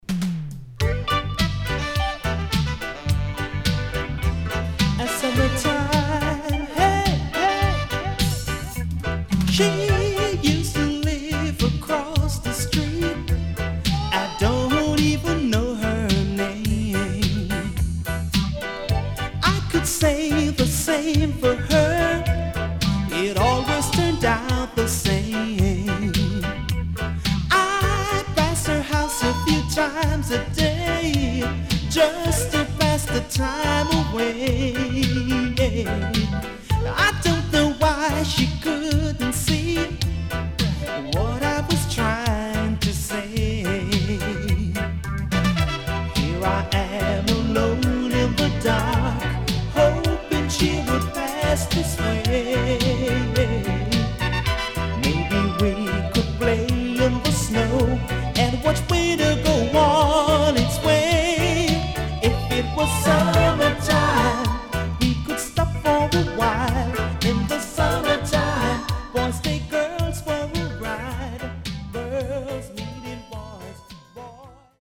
CONDITION SIDE A:VG+
Nice Mellow Lovers
SIDE A:少しチリノイズ入ります。